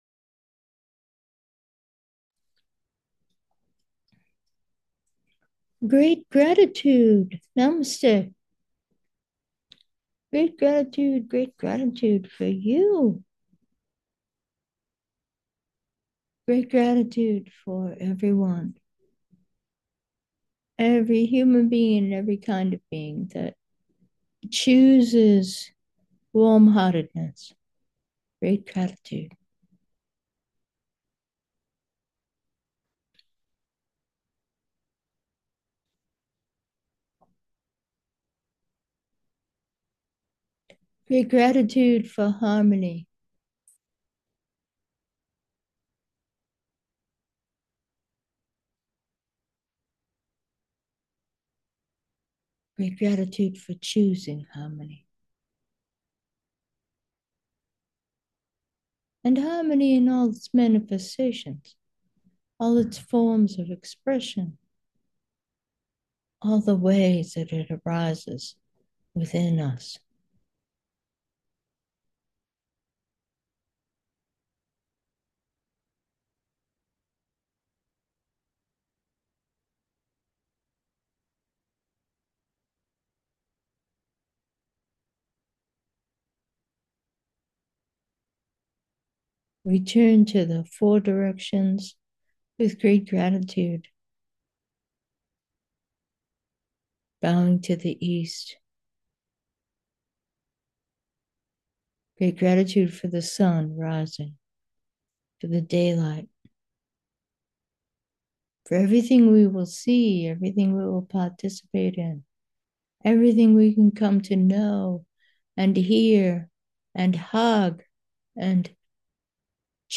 Meditation: The Four Directions, great gratitude